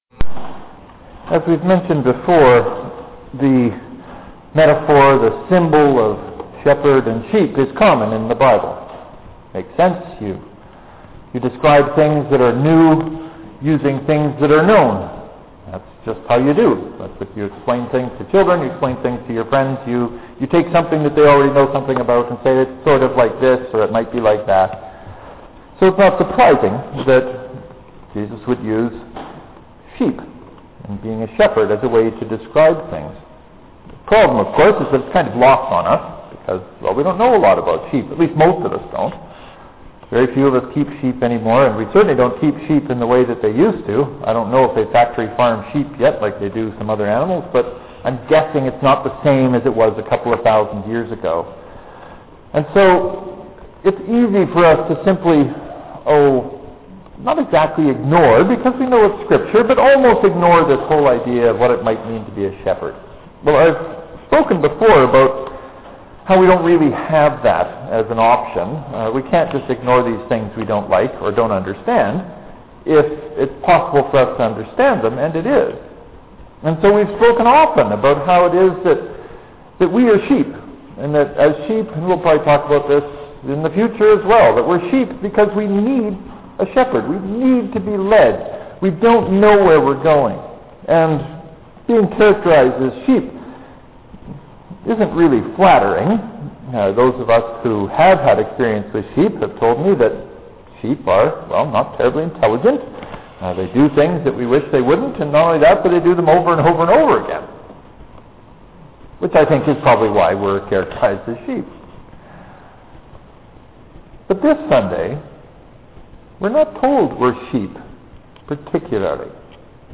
One of the points I try to make in the sermon, of which there is only one this week*, is that we are all called to be shepherds.
St. Mark’s Presbyterian 130414_StMarks *As I think I have mentioned, okay complained about, this has been the very worst winter ever for driving.